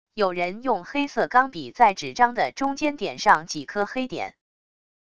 有人用黑色钢笔在纸张的中间点上几颗黑点wav音频